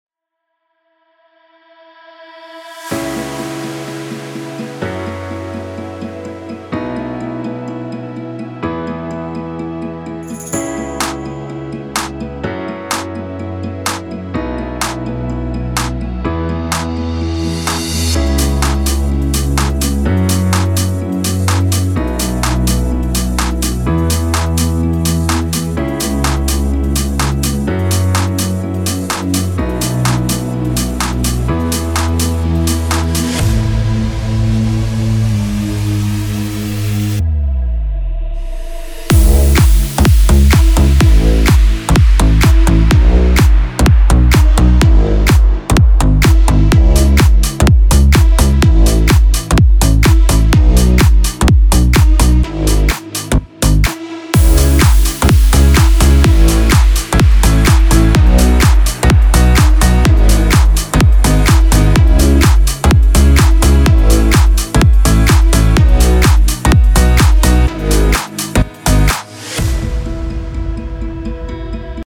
Popschlagersong im aktuellen Style.
Hier kannst du kurz ins Playback reinhören.
BPM – 126
Tonart – E-major